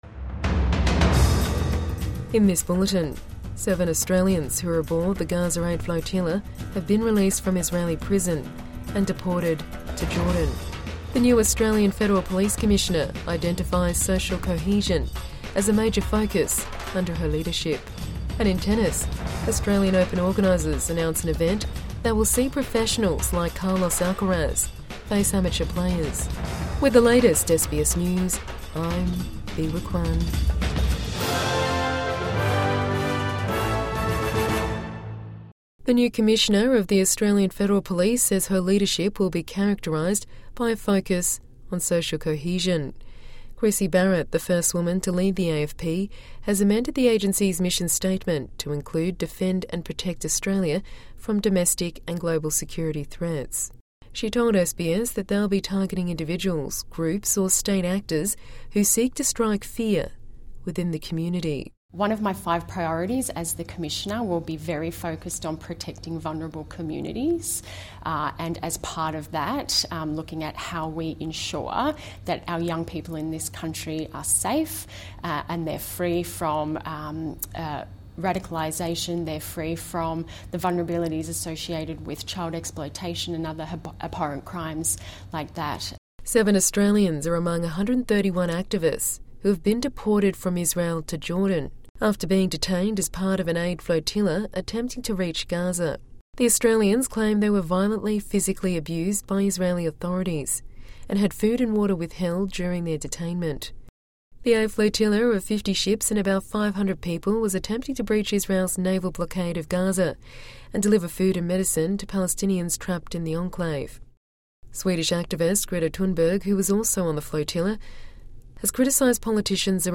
Australians released from Israeli prison and deported to Jordan | Morning News Bulletin 8 October 2025